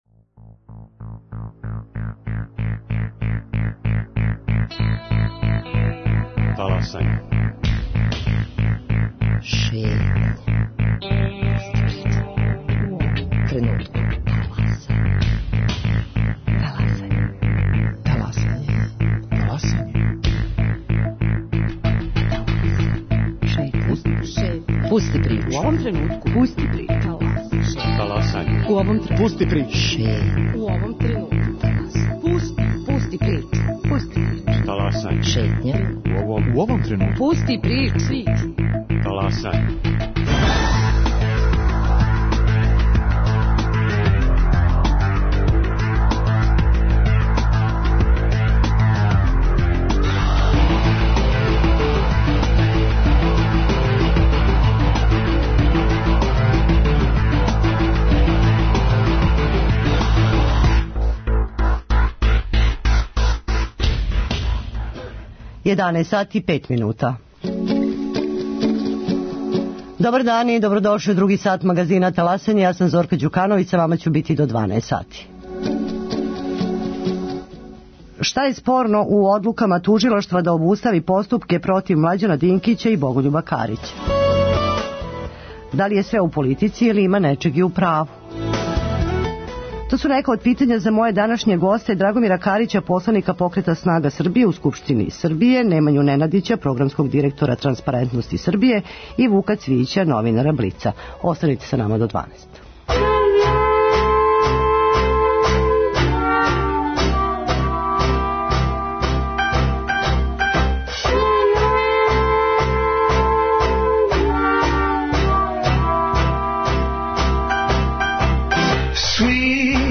Гости емисије: Драгомир Карић, посланик Покрета снага Србије